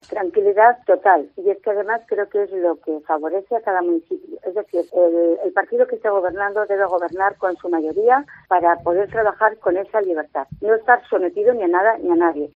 La alcaldesa electa de Alfaro ha pasado por los micrófonos de COPE para valorar esta victoria electorial. Yolanda Preciado pone en valor la "libertad" que otorga una mayoría absoluta.